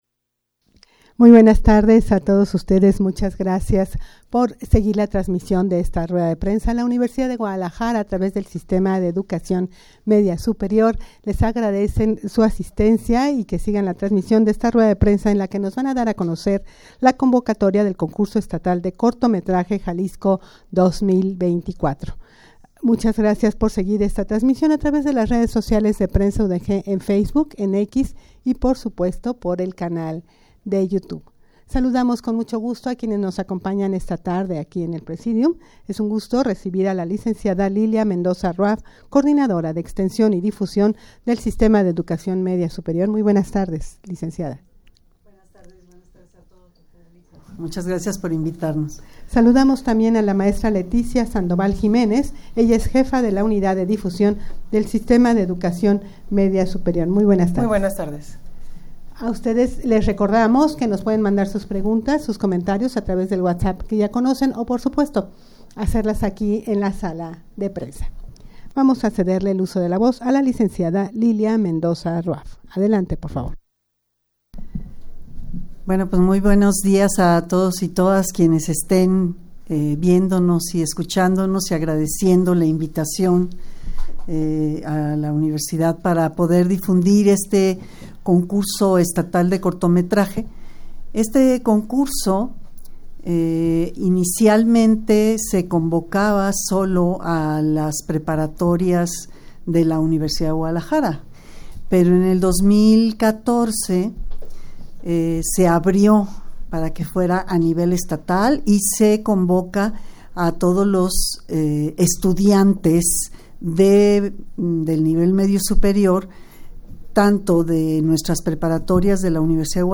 Audio de la Rueda de Prensa
rueda-de-prensa-para-dar-a-conocer-la-convocatoria-del-concurso-estatal-de-cortometraje-jalisco-2024.mp3